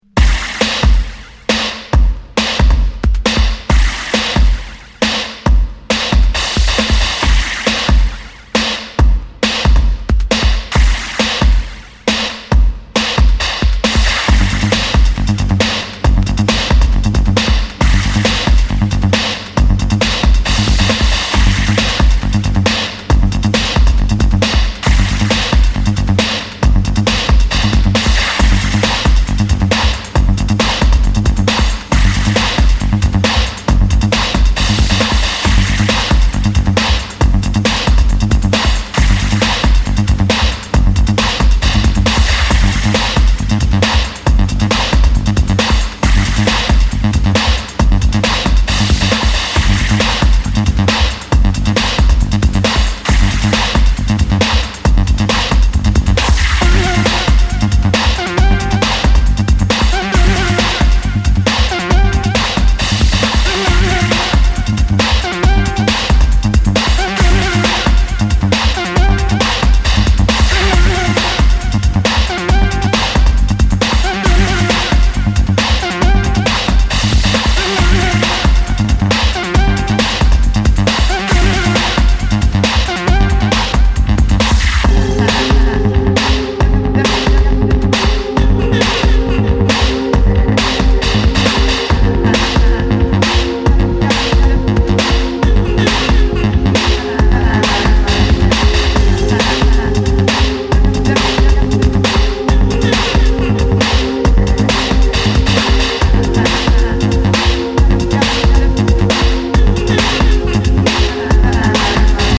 尖ったインダストリアル・ビート主体ながらエレクトロ、テクノ、ミニマルをもACID感覚も絡めつつ突き進む狂気の全13曲！